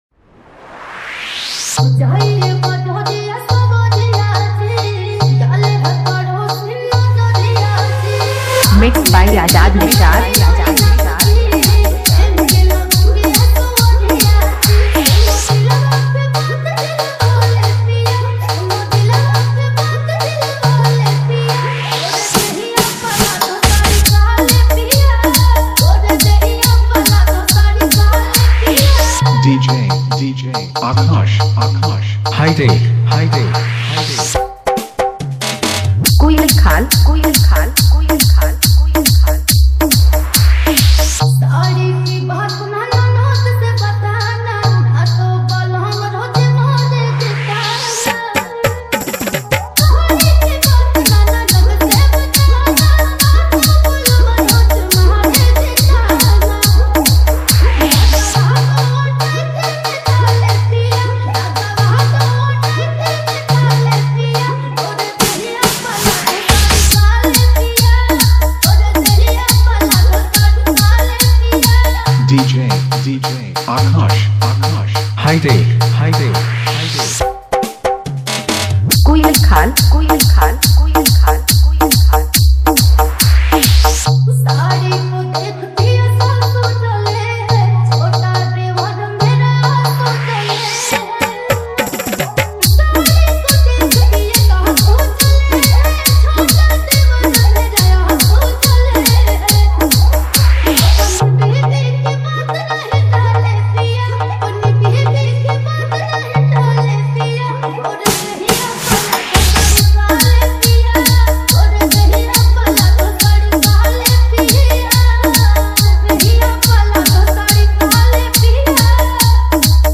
Bhojpuri Love DJ Remix